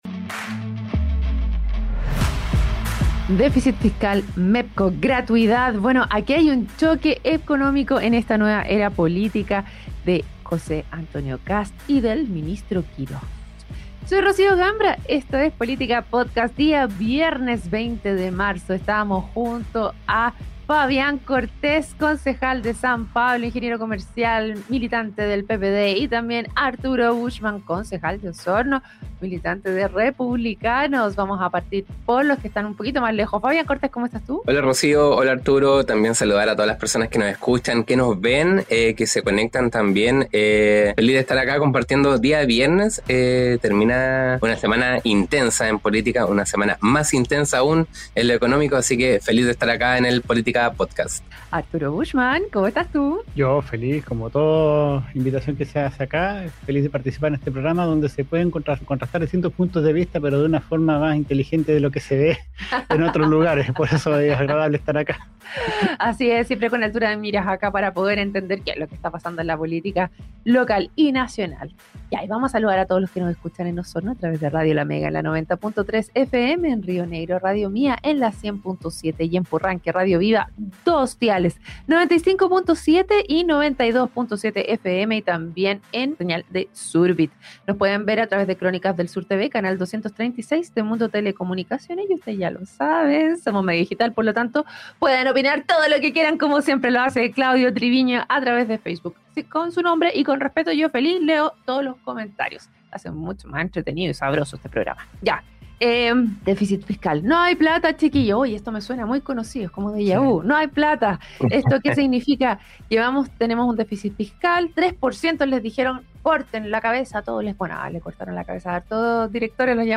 Los concejales Fabián Cortés y Arturo Buschmann abordaron el déficit fiscal , posibles reformas al MEPCO y gratuidad .